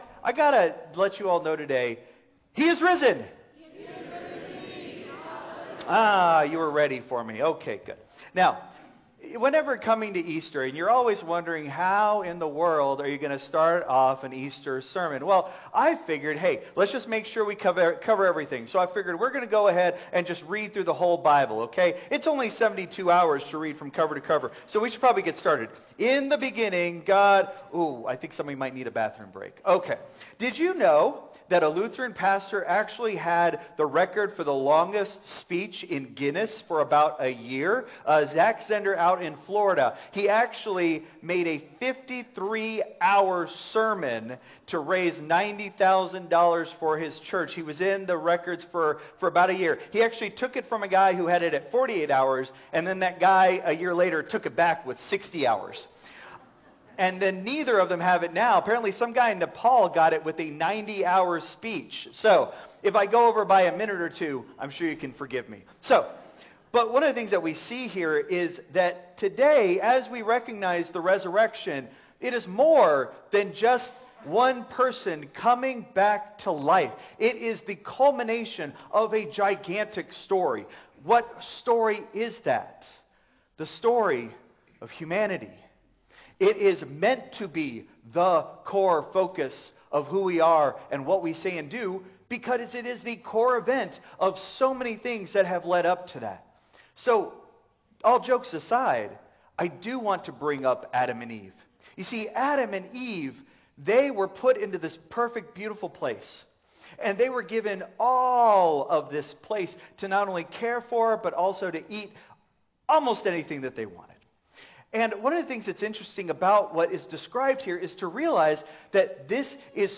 Christ Memorial Lutheran Church - Houston TX - CMLC 2025-04-20 Sermon (Contemporary)